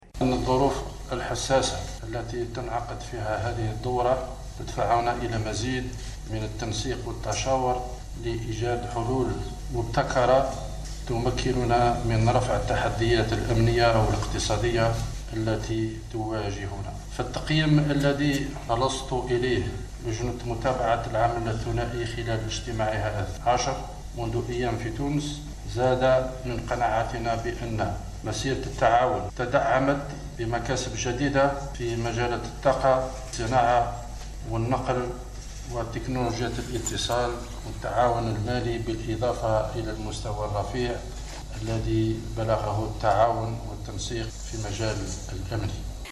تصريح الوزير الأول عبد المالك سلال بشأن ضرورة تعزيز العلاقات و المزيد من التشاور بين البلدين